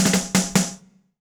British SKA REGGAE FILL - 05.wav